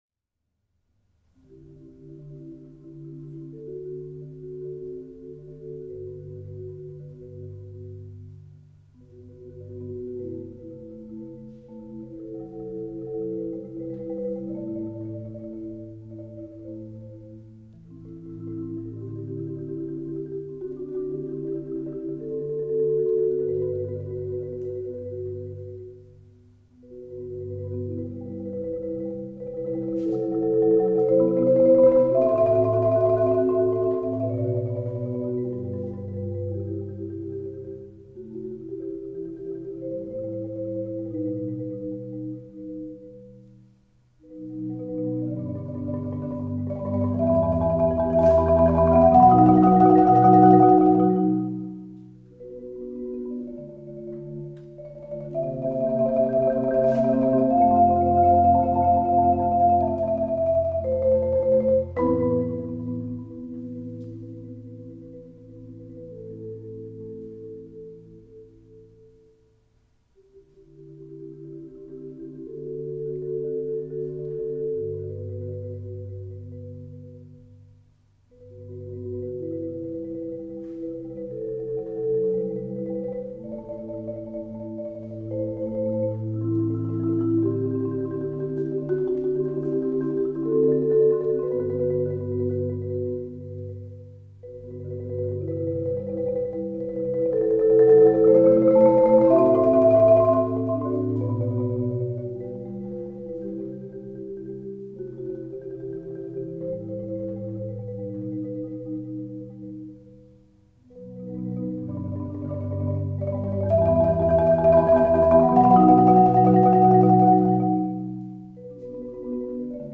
Genre: Marimba Quartet
Marimba 1 (4-octave)
Marimba 2 (4-octave)
Marimba 3 (4-octave)
Marimba 4 (4.5-octave)